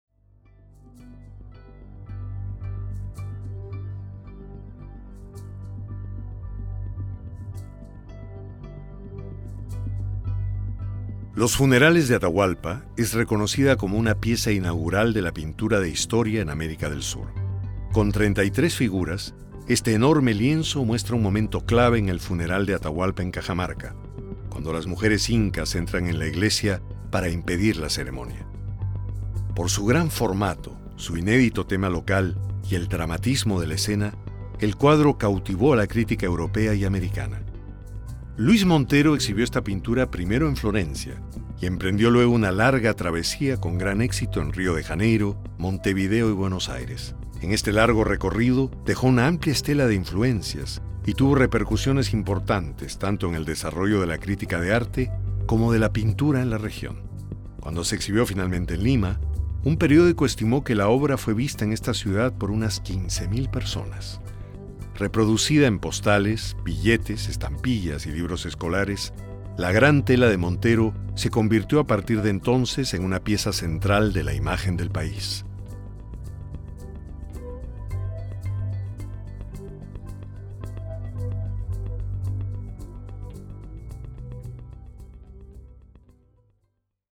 AUDIOGUÍA EN ESPAÑOL